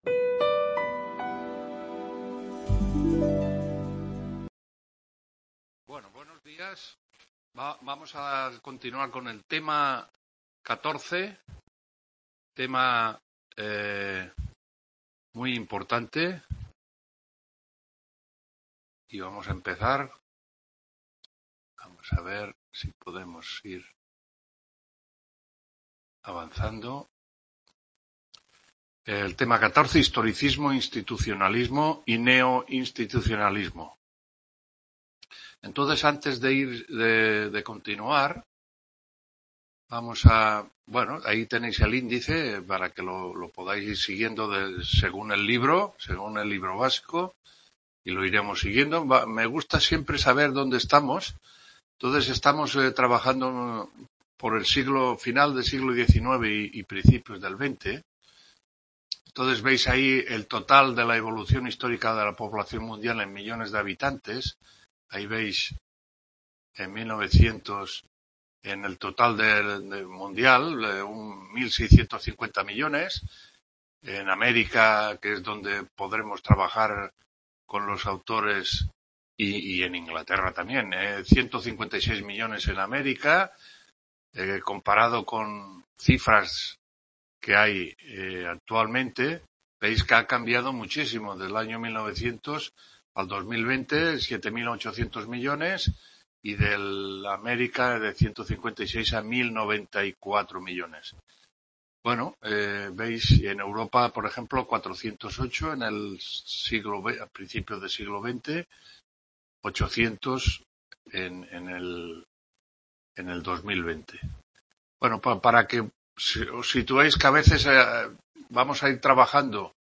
TUTORÍA INTERCAMPUS